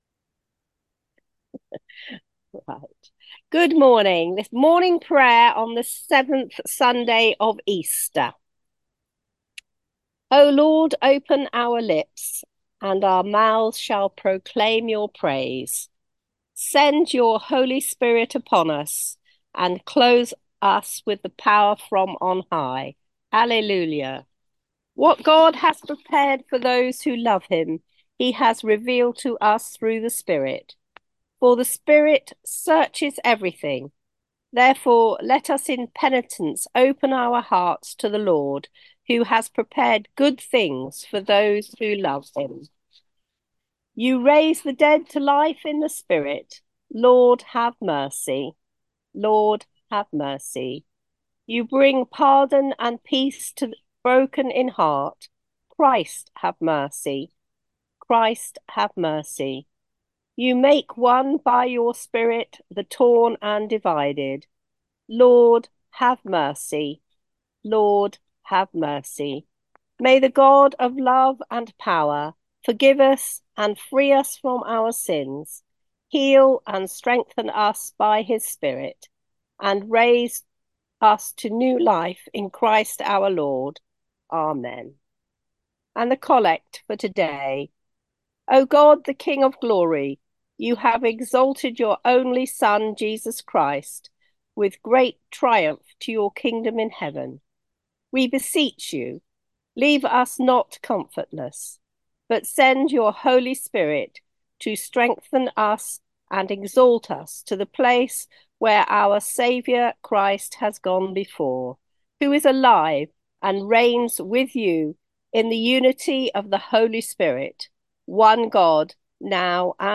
The audio from the Zoom / Conference Call service on 24/03/2024.